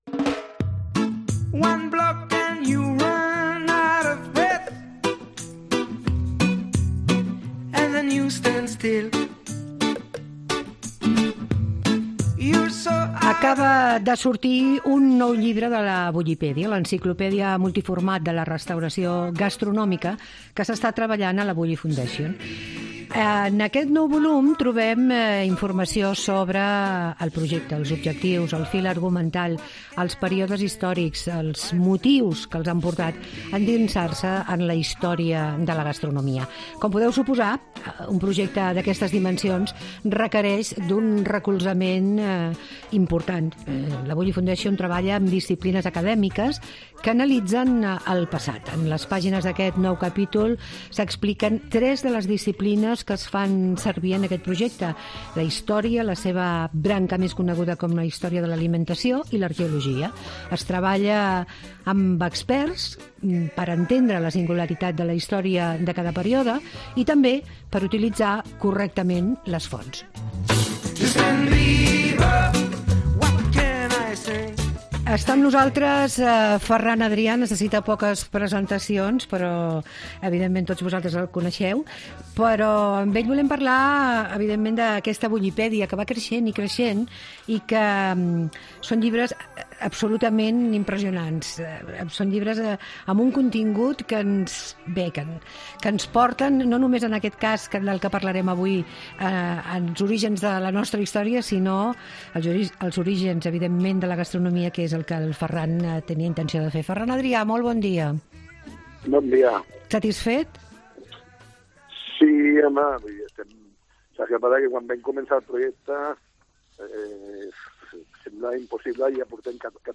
Ferran Adrià, ens presenta l'últim llibre d'aquesta important enciclopèdia de la restauració gastronòmica.